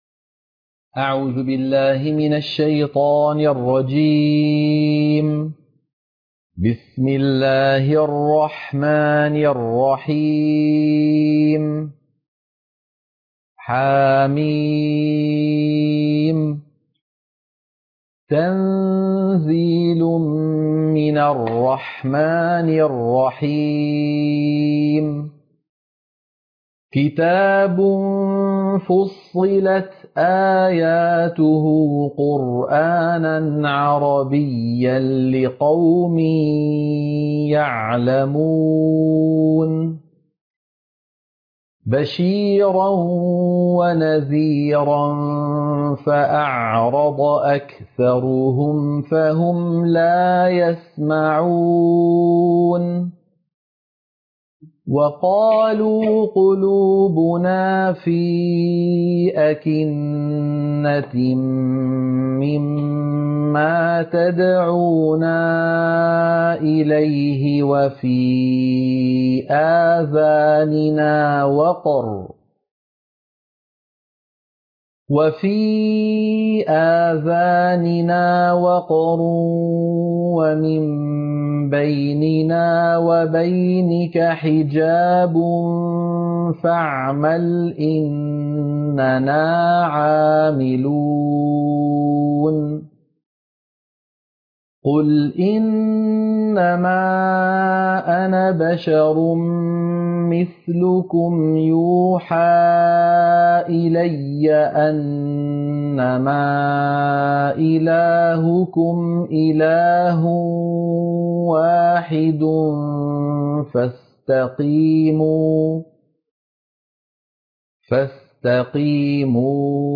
سورة فُصِّلت - القراءة المنهجية - الشيخ أيمن سويد